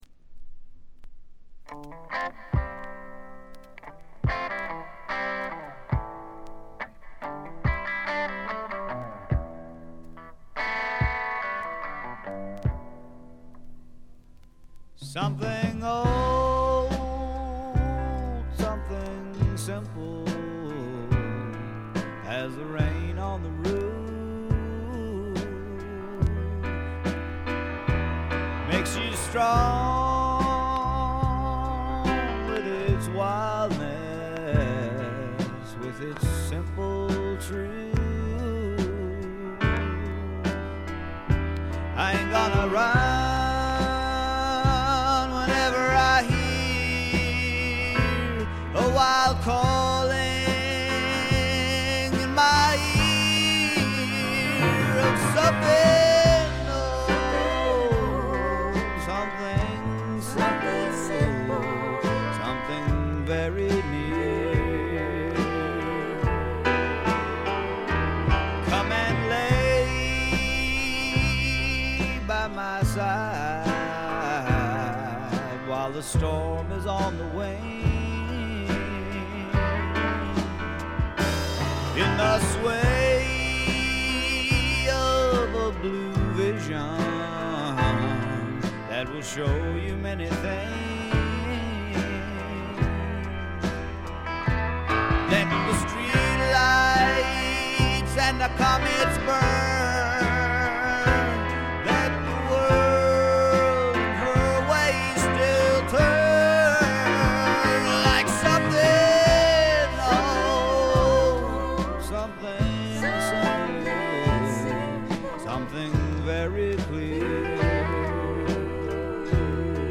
軽微なバックグラウンドノイズ、チリプチ程度。
聴くものの心をわしづかみにするような渋みのある深いヴォーカルは一度聴いたら忘れられません。
試聴曲は現品からの取り込み音源です。